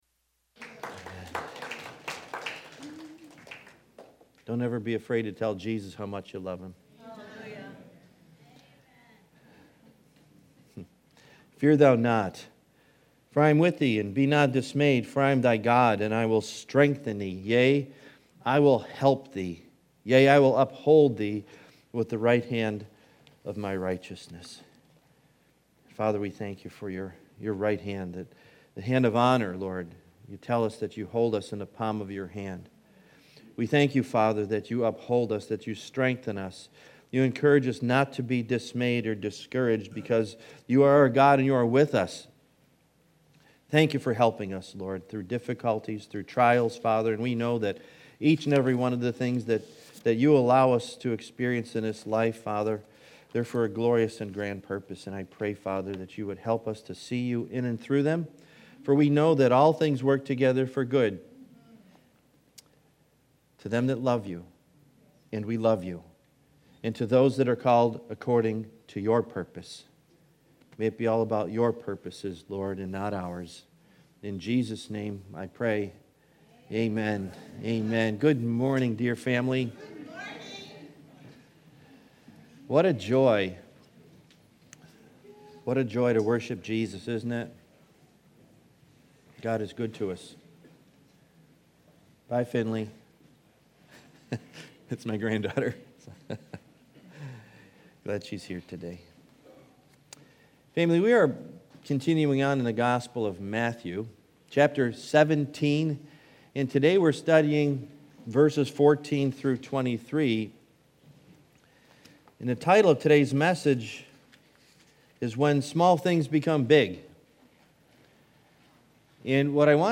Series: Sunday Morning